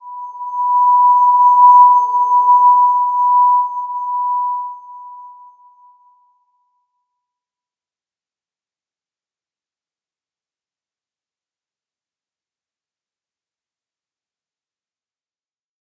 Slow-Distant-Chime-B5-p.wav